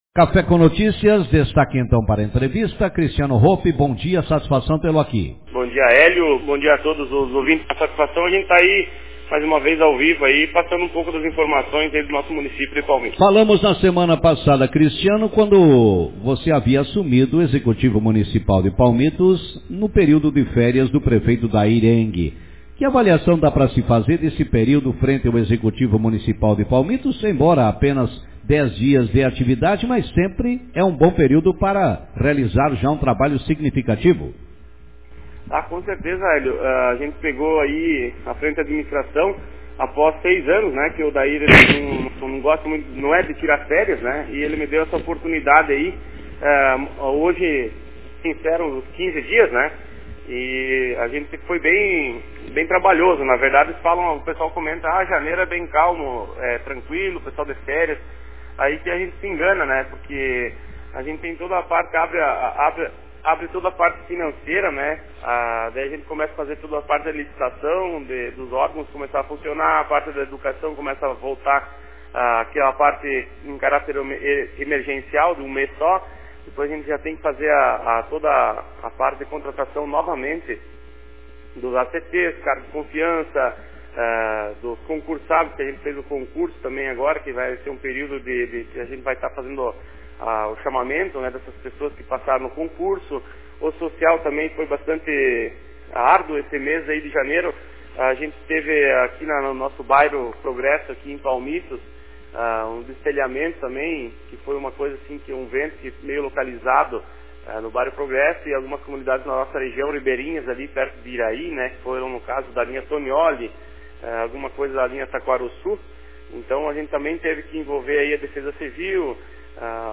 Vice-prefeito de Palmitos avalia atuação frente ao Executivo Autor: Rádio Marabá 17/01/2023 Manchete Nesta manhã, no programa Café com Notícias, o vice-prefeito de Palmitos, Cristiano Hoppe, avaliou o período que esteve à frente da Administração Municipal, em função das férias do prefeito Dair Jocely Enge. Acompanhe a entrevista